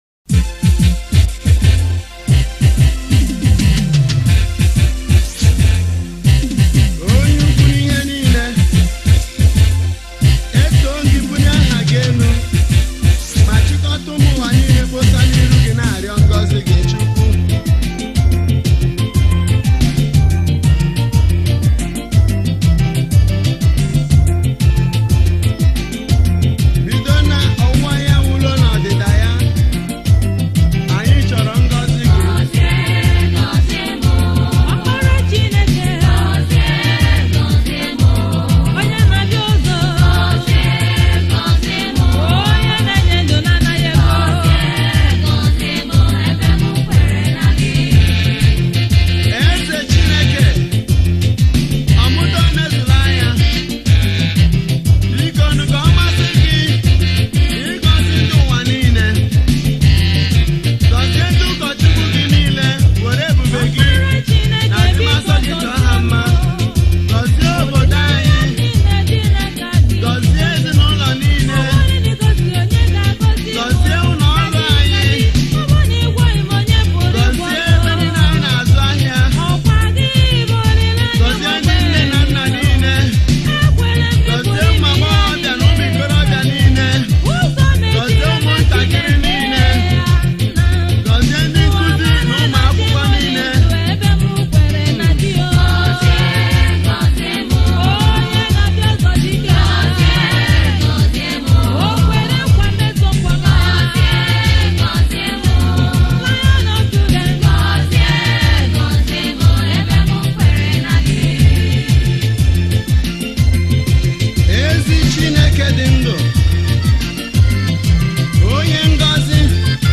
February 10, 2025 Publisher 01 Gospel 0